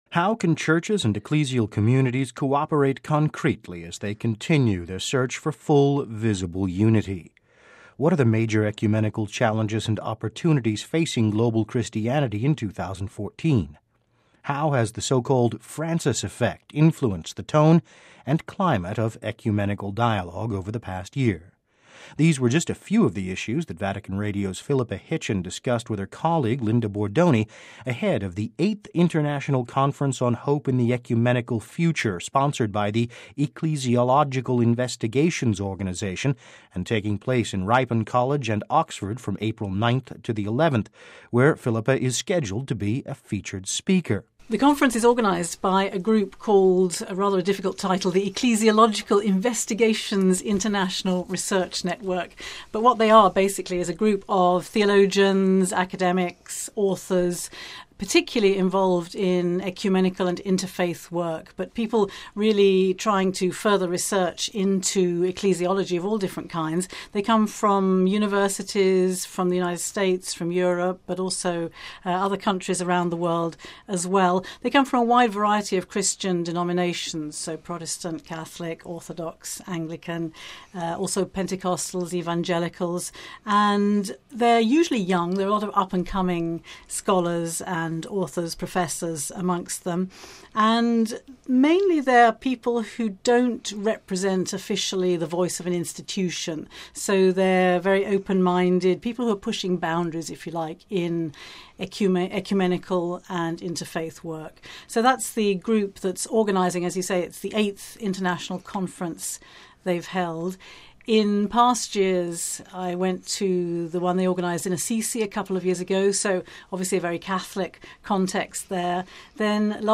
extended conversation